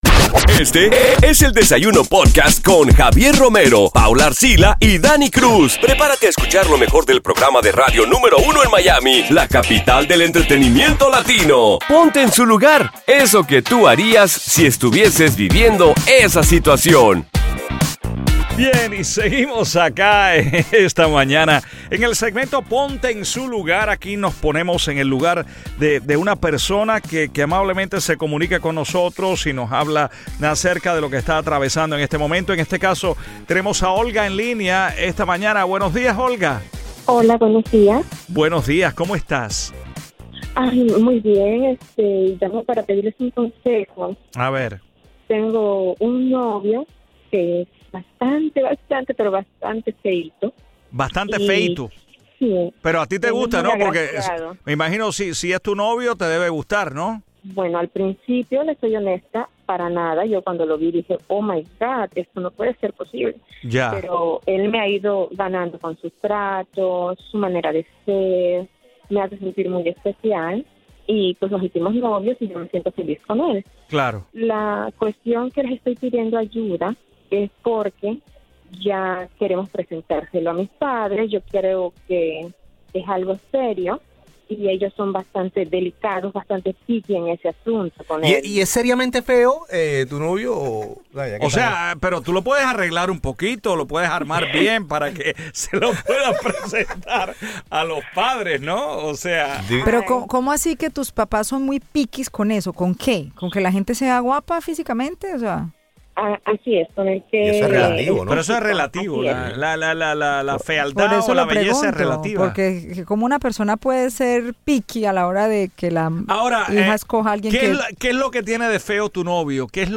Entrevista con Maluma, Latinos en Miami y mucho mas...